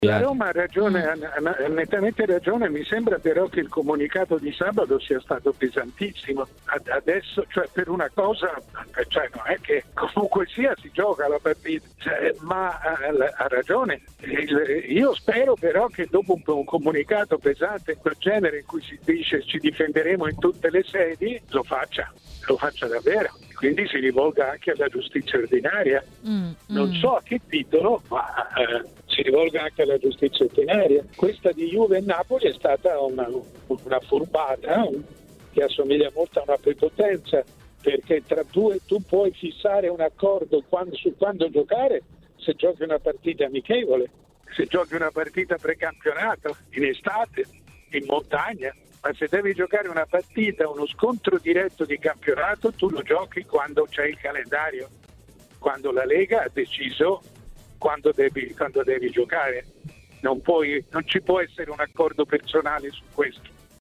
A TMW Radio, durante Maracanà, il direttore Mario Sconcerti ha detto la sua sul rinvio di Juventus-Napoli e le proteste della Roma, che incontrerà i partenopei proprio il prossimo weekend: "Ha la ragione la Roma, il comunicato di sabato è stato pesantissimo.